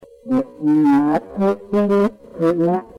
In questa brevissima registrazione ricevuta il 24 novembre 2008, si presenta un'entità sconosciuta che, però, dal tono che usa, sembra avere una certa autorità.